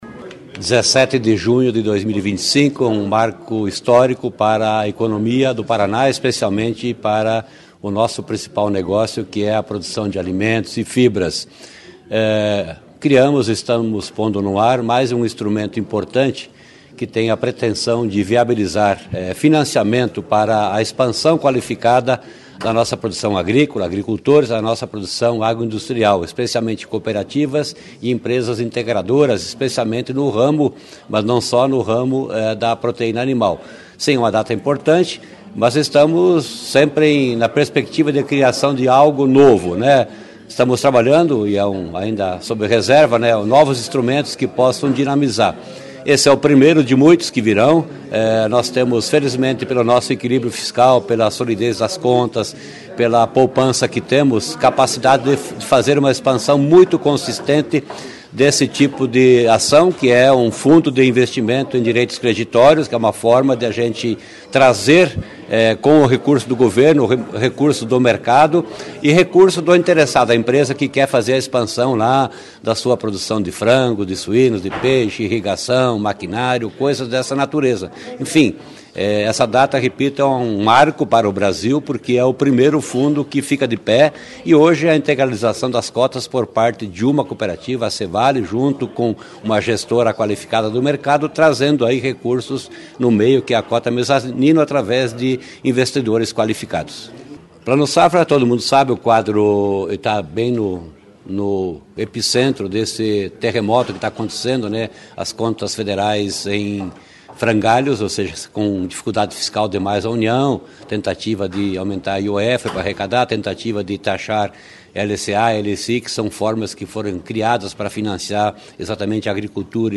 Sonora do secretário Estadual da Fazenda, Norberto Ortigara, sobre o primeiro aporte do FIDC Agro Paraná